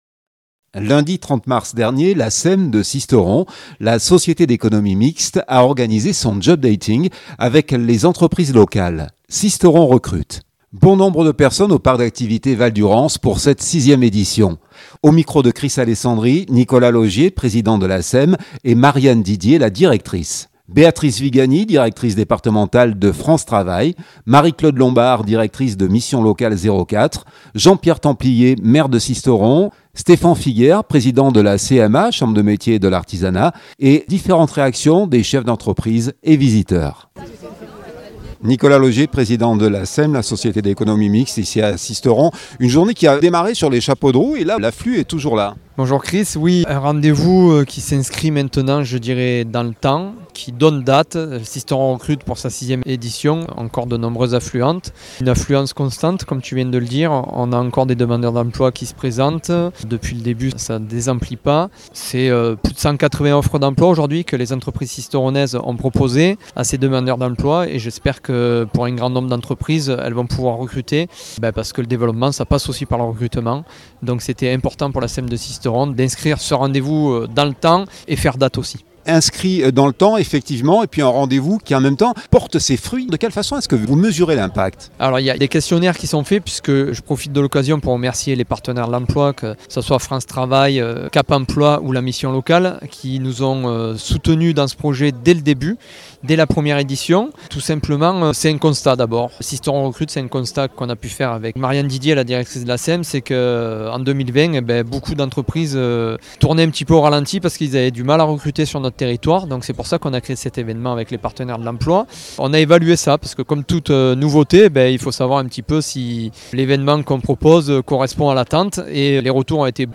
Bon nombre de personnes au Parc d’activités Val Durance pour cette sixième édition.